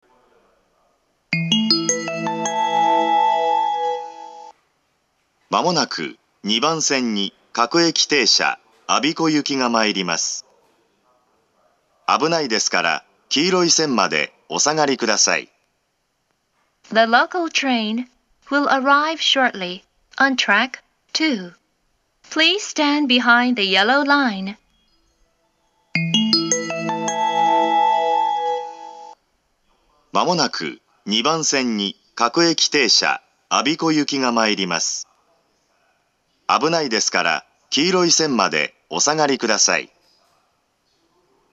２番線接近放送
２番線到着放送
車両の乗降促進メロディーで発車します。